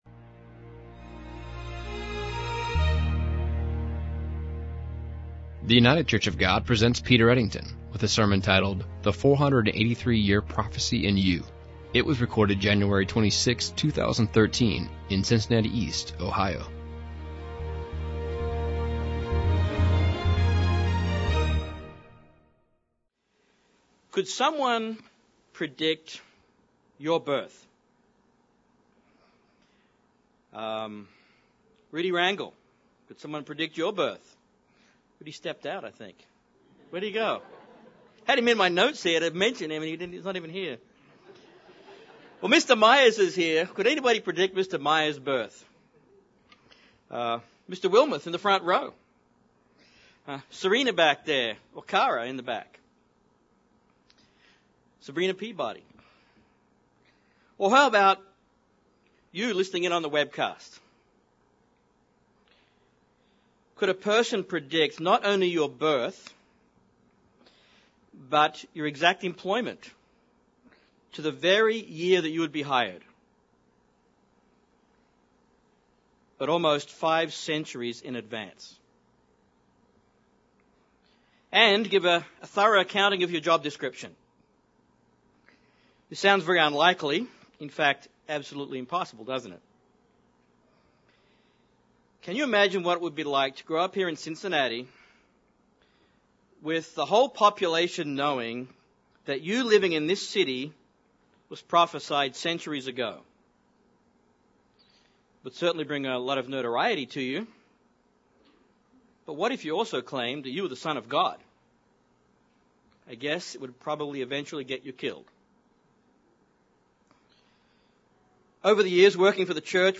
In this sermon, we are going to look at just one particular prophecy about the ministry of Jesus Christ and reflect on how it affects you.